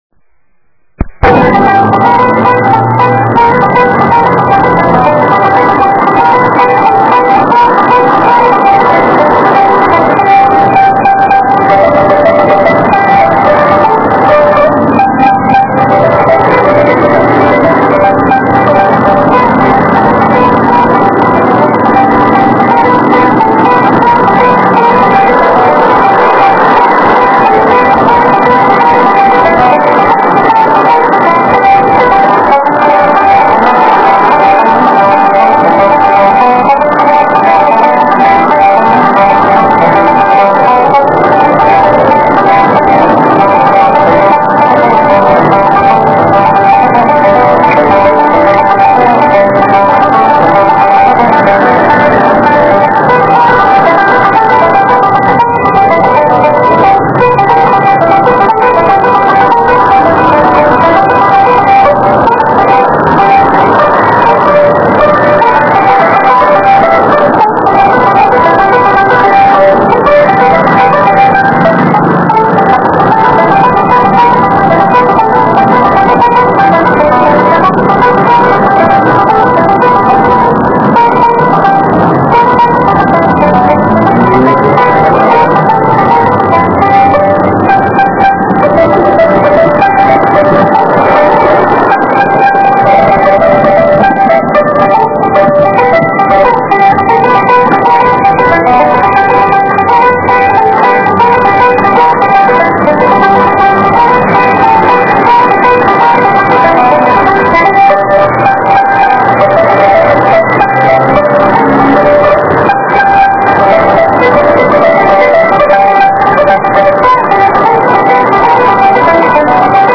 Banjo
A charming young man sat on a chair by a Jewish synagogue, plucking his banjo with great passion, as noisy cars rattled by.
Bluegrass banjo on Dolores Street, San Francisco